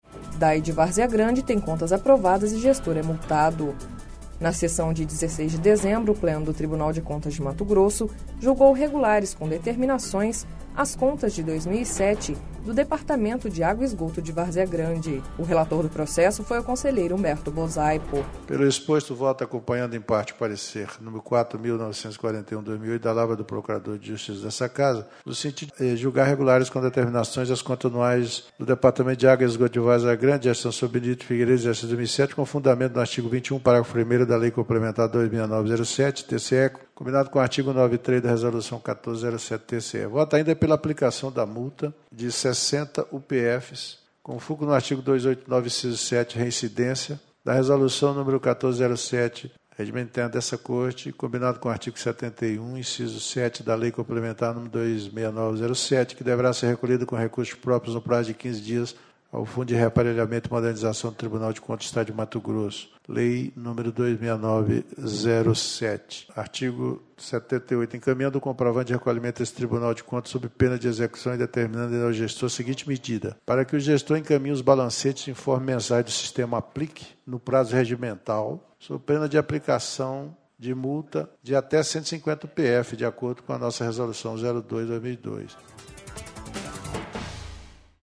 Na sessão de 16 de dezembro, o Pleno do Tribunal de Contas de Mato Grosso julgou regulares com determinações as contas de 2007 do Departamento de Água e Esgoto de Várzea Grande (DAE/VG)./ O relator do processo foi o conselheiro Humberto Bosaipo.// Sonora: Humberto Bosaipo – conselheiro do TCE- MT